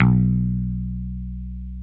Index of /90_sSampleCDs/Roland L-CDX-01/GTR_Dan Electro/BS _Dan-O Bass